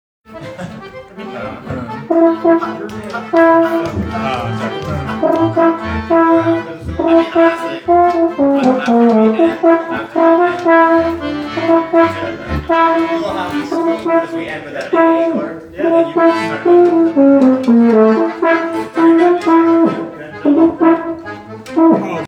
Dort midhorn lick .m4a